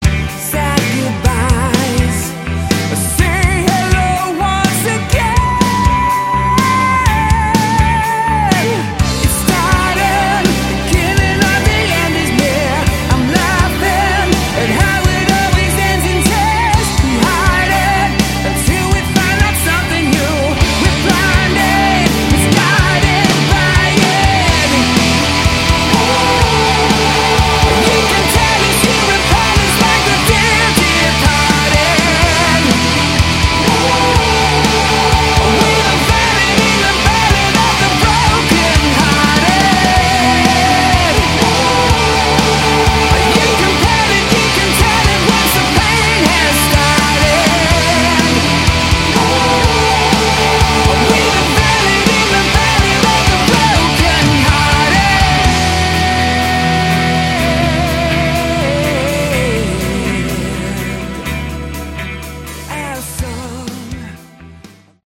Category: Melodic Rock
vocals
drums
bass
keyboards
guitar